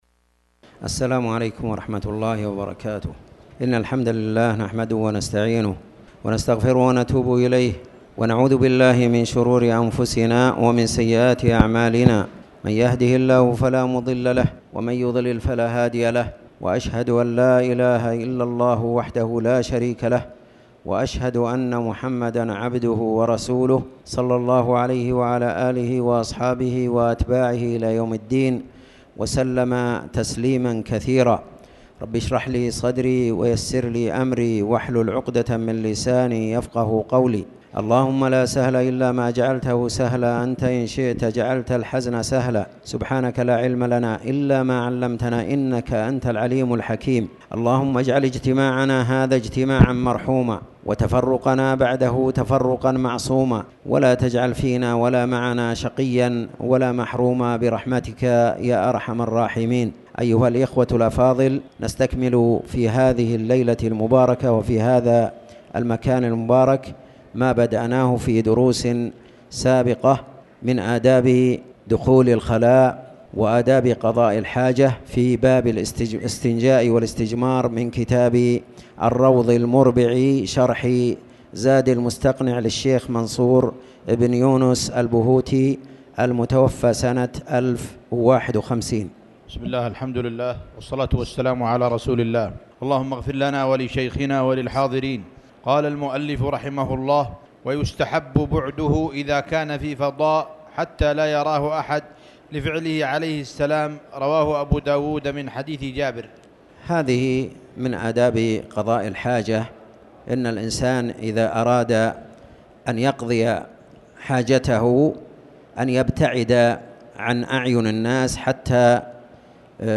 تاريخ النشر ٢٦ جمادى الأولى ١٤٣٩ هـ المكان: المسجد الحرام الشيخ